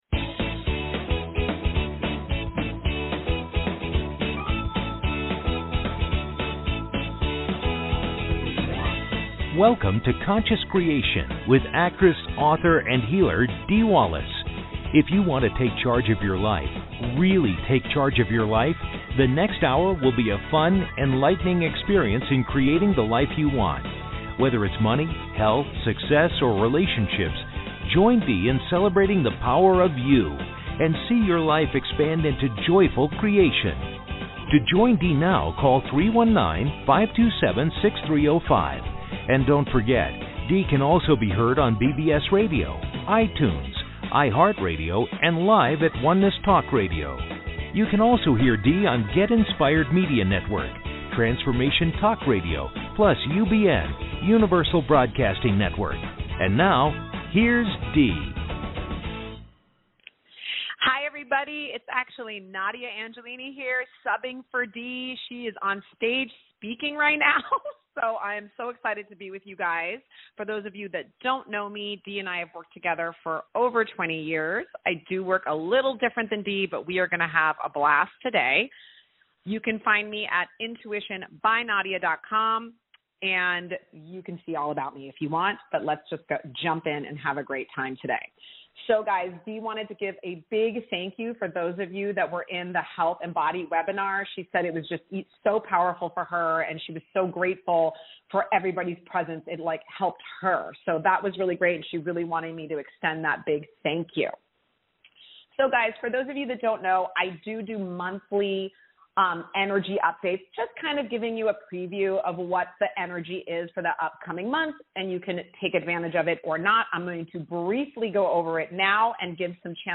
Talk Show Episode, Audio Podcast, Conscious Creation and with Dee Wallace on , show guests , about Dee Wallace,conscious creation,I am Dee Wallace, categorized as Kids & Family,Philosophy,Psychology,Self Help,Society and Culture,Spiritual,Access Consciousness,Medium & Channeling,Psychic & Intuitive